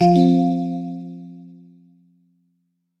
message_received.ogg